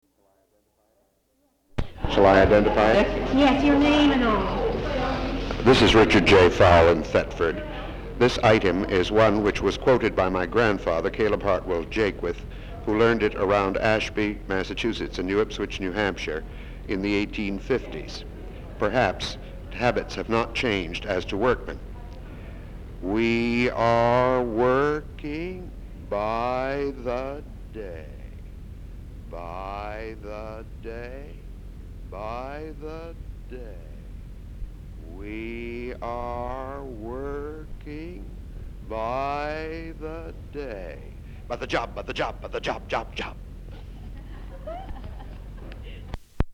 Folk songs, English--Vermont
sound tape reel (analog)
Location Thetford, Vermont